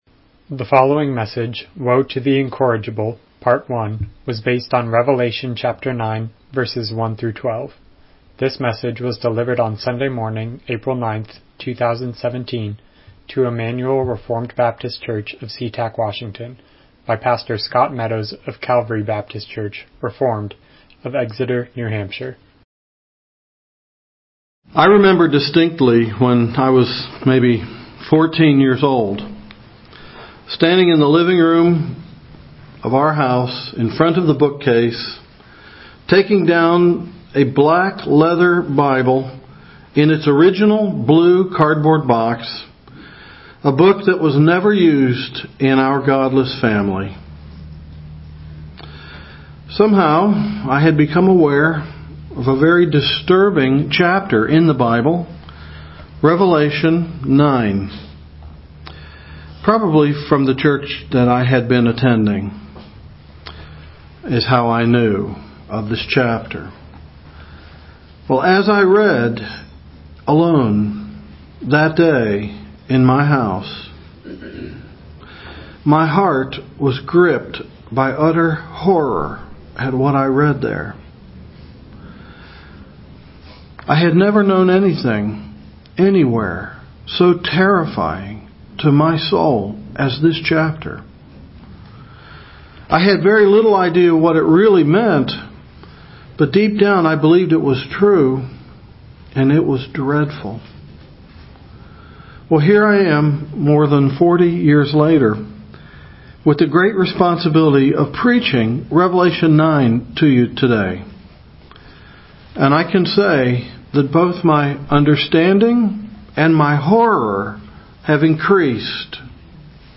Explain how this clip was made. Miscellaneous Passage: Revelation 9:1-12 Service Type: Morning Worship « Personal Testimony Woe to the Incorrigible